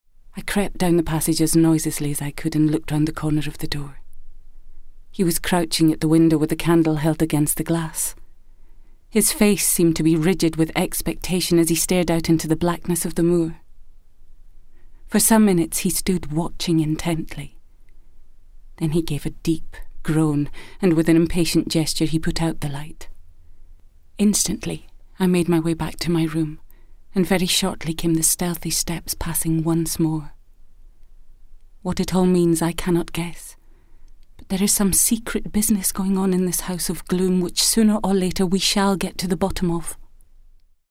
Sophisticated, Warmhearted and Gracious. Native dialect: Scots (Central).
Prose